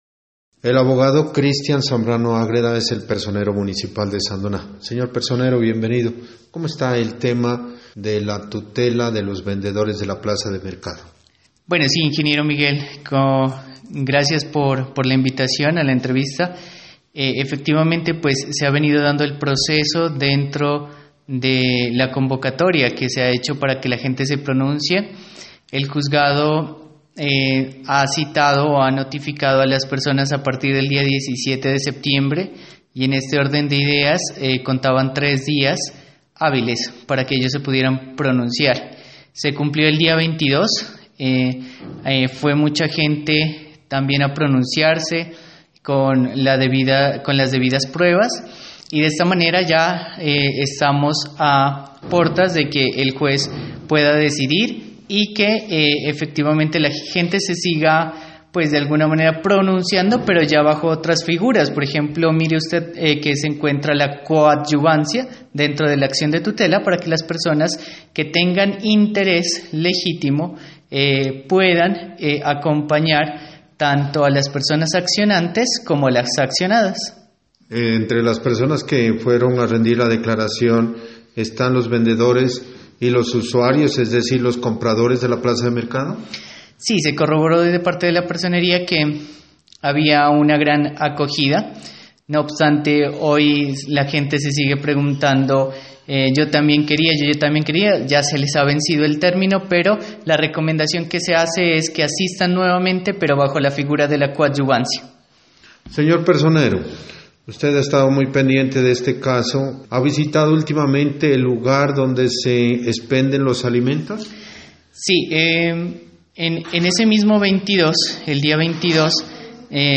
Entrevista con el personero de Sandoná Christian Zambrano Ágreda: